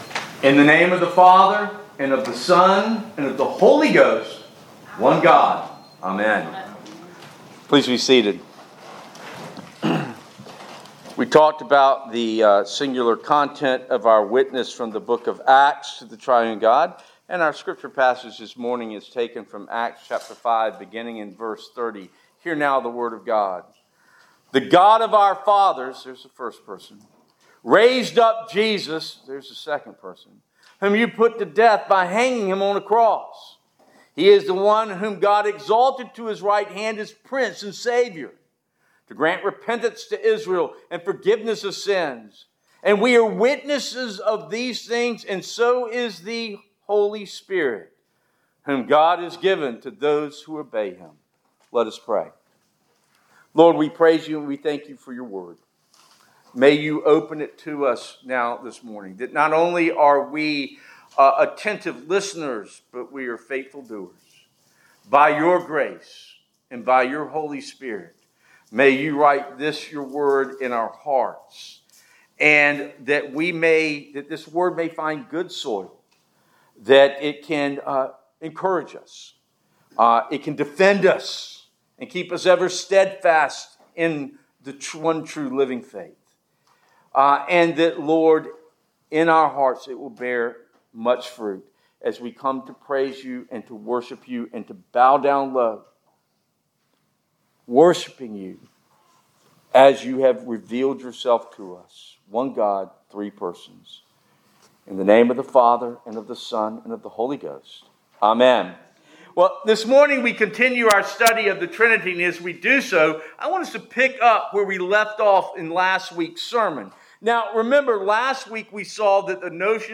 Introduction This morning we continue our study of the Trinity and as we do so I want us to pick up where last week’s sermon left off.